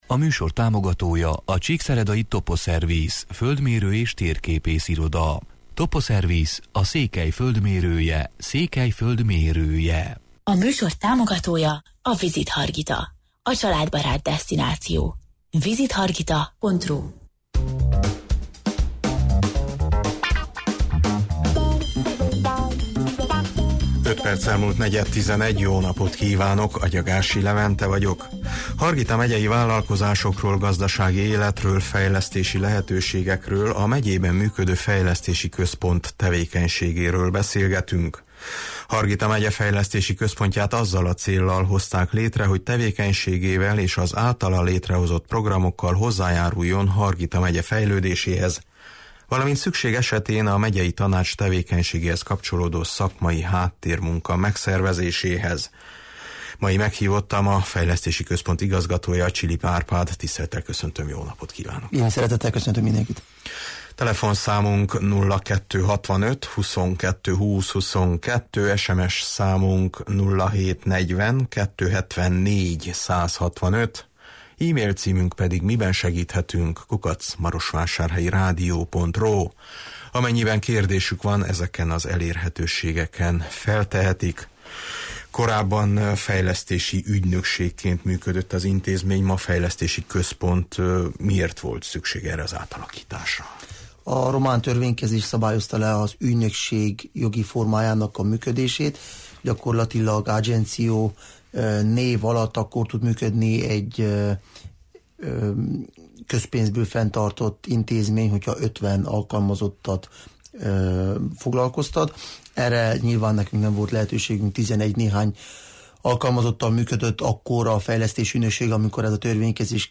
Hargita megyei vállalkozásokról, gazdasági életről, fejlesztési lehetőségekről, a megyében működő fejlesztési központ tevékenységéről beszélgetünk.